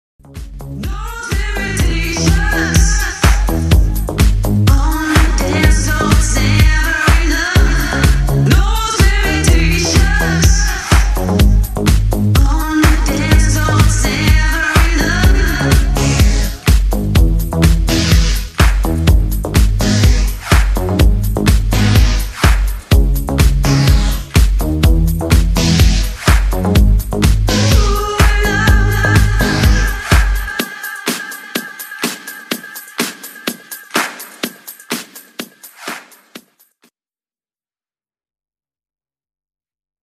ритмичные
громкие
женский вокал
deep house
Tech House